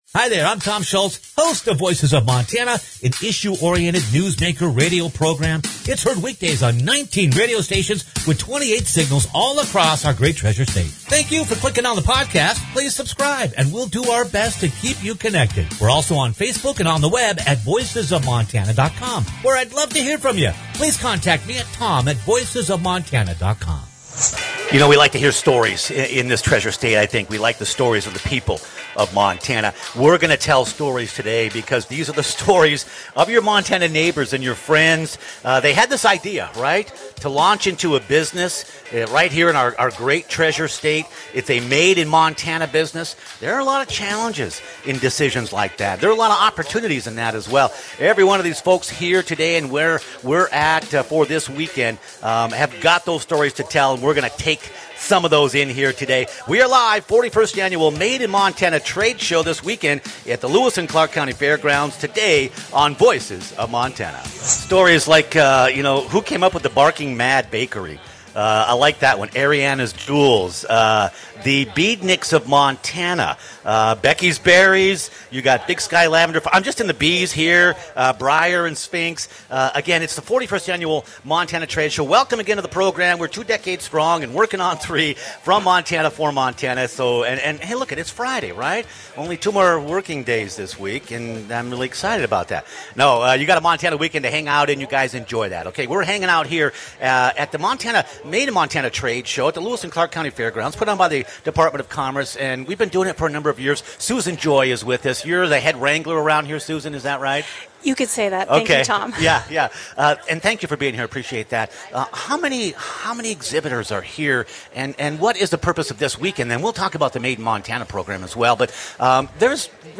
They are the stories of your Montana neighbors and friends who launched into a business venture in our great state. The 41st annual Made in Montana Trade Show takes place today and we’re live from the Lewis and Clark County Fairgrounds to highlight numerous Montana entrepreneurs and